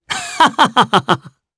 Clause-Vox_Happy3_jp.wav